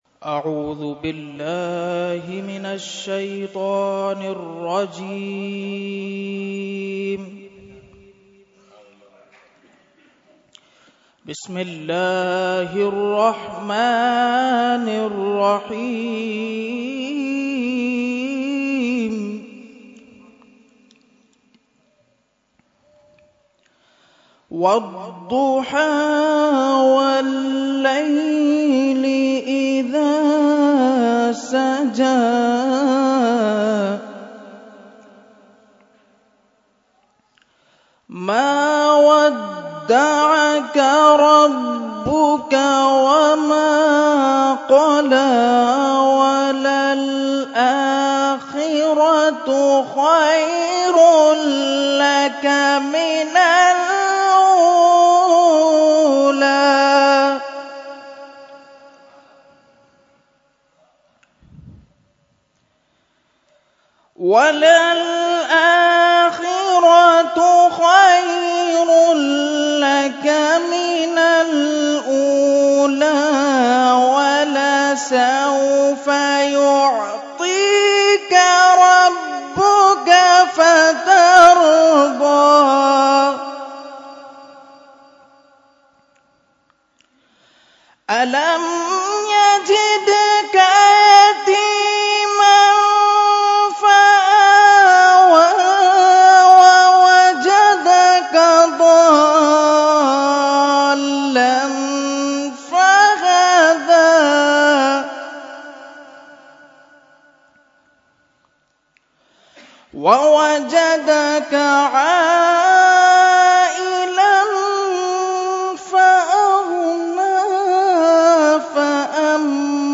Qirat – 11veen Shareef 2018 – Dargah Alia Ashrafia Karachi Pakistan